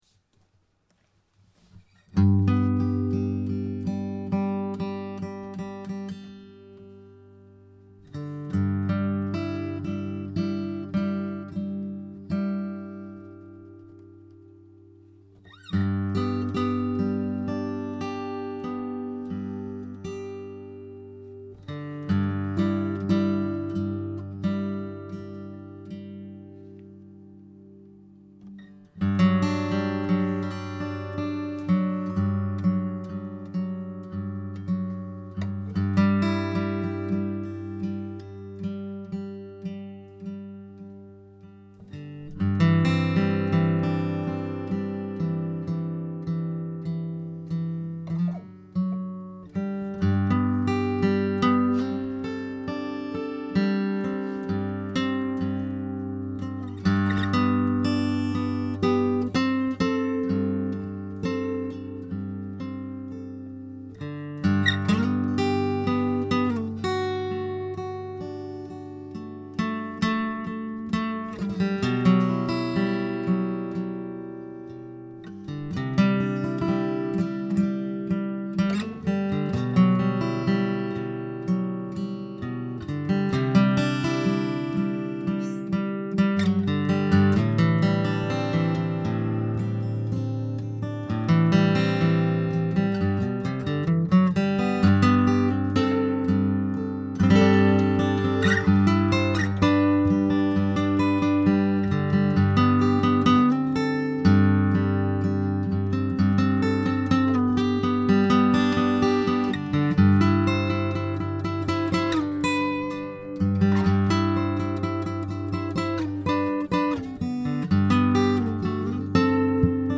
10:57 Guitar Meditation for the Fourth Week of Advent 11:29 Guitar Meditation for the Third Week of Advent 13:34 Guitar Meditation for the Second Week of Advent 11:39 Guitar Meditation for the First Week of Advent